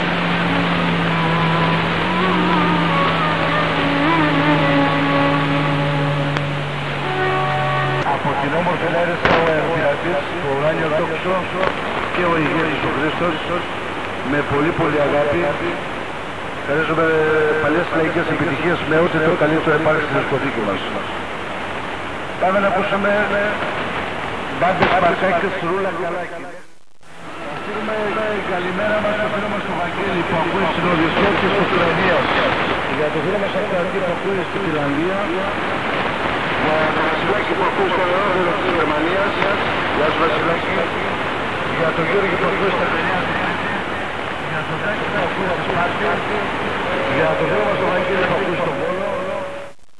Pirate radio from Greece received in Finland
I have 300 meters longwire directed to South and it gives good signals.